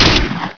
shotg_fire.wav